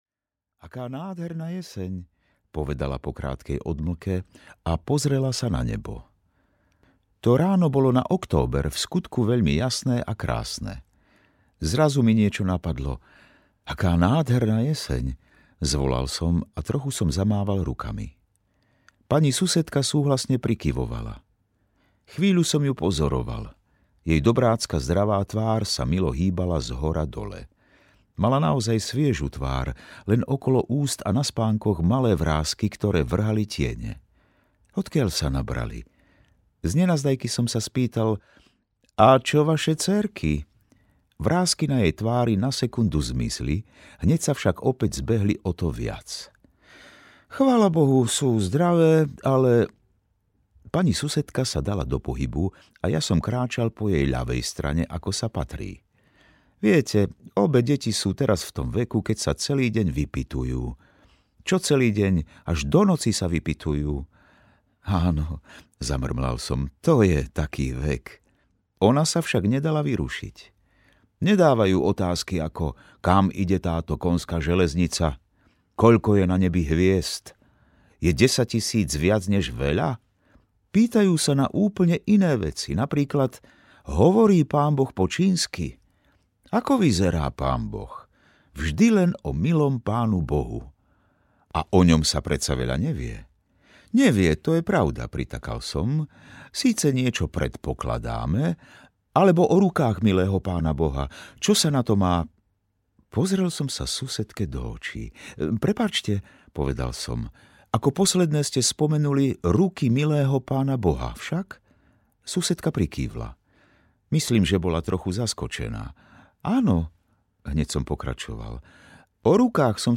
Audio knihaPríbehy o milom Pánu Bohu
Ukázka z knihy
• InterpretJán Gallovič